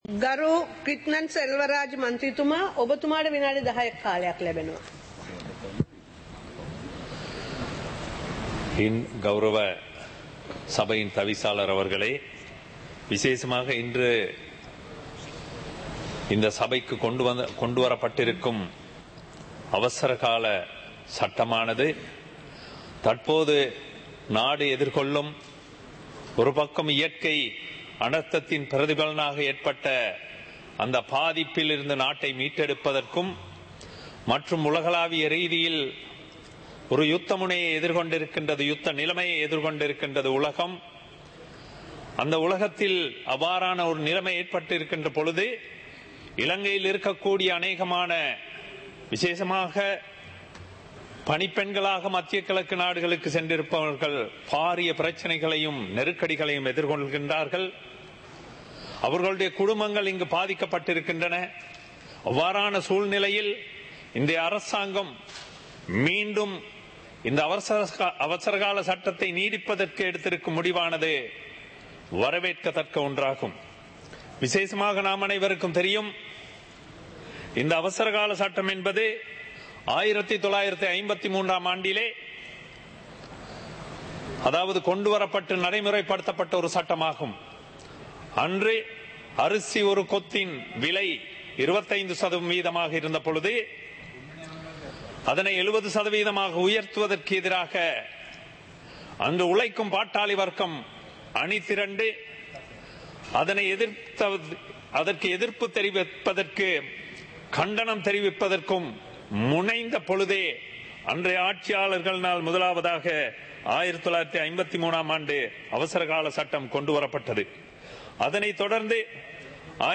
Parliament of Sri Lanka - Proceedings of the House (2026-03-06)
Parliament Live - Recorded